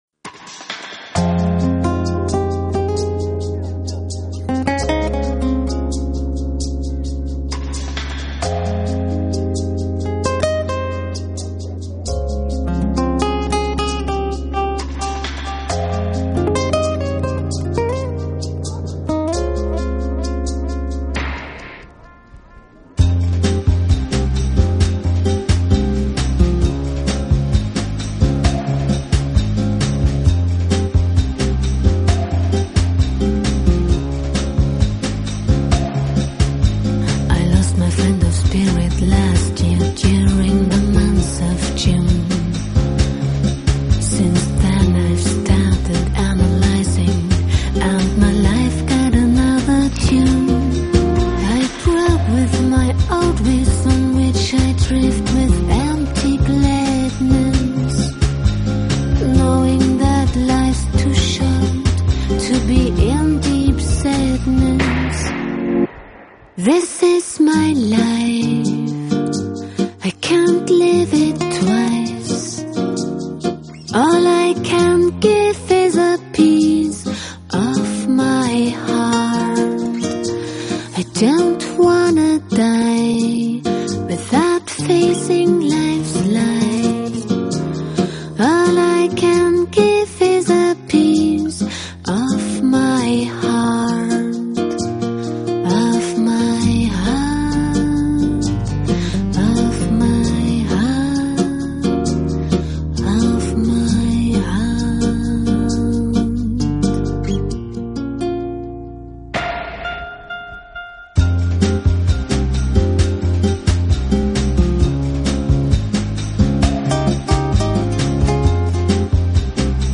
Bossa Nova那種舒適耽美的氛圍。
響，甚至嘗試了加入Tango 節奏而呈現另一番風味。